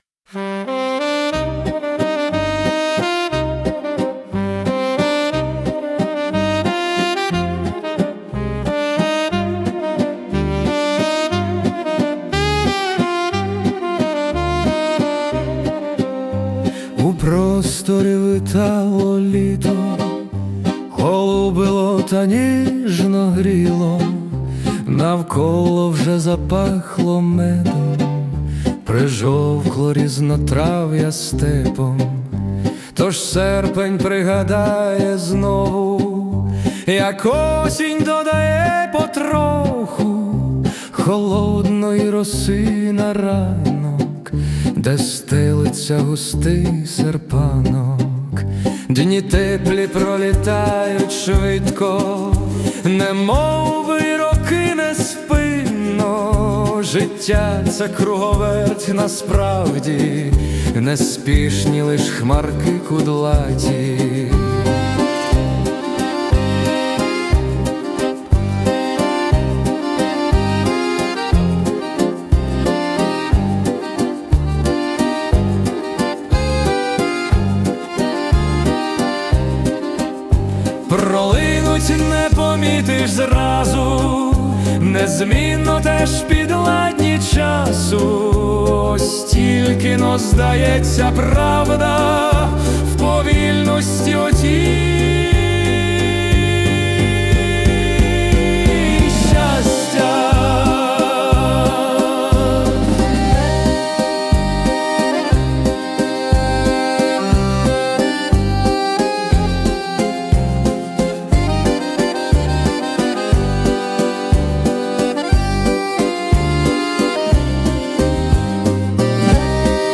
Музична композиція створена за допомогою SUNO AI
Гарна танцювальна мелодія на чудові слова про літечко.
12 12 12 Цей літньо-передосінній вальс зачарував!